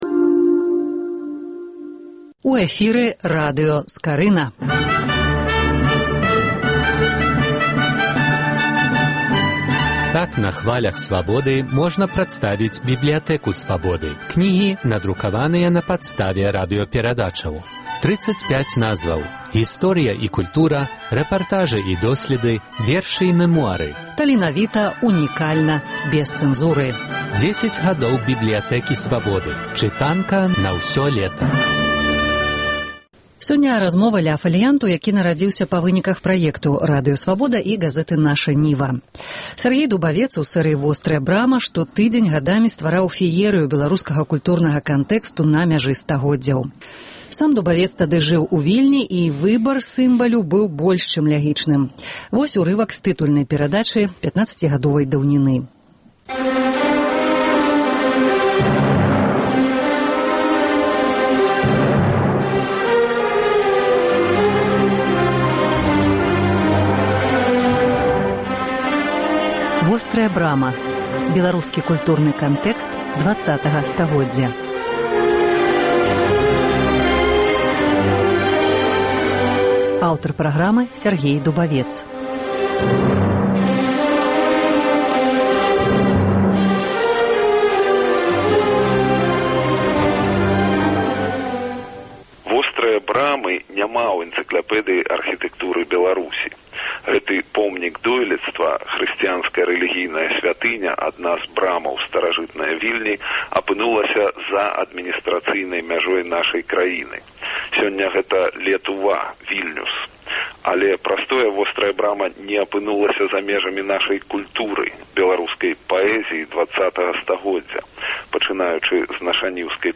Працяг радыёсэрыі “10 гадоў “Бібліятэкі Свабоды”.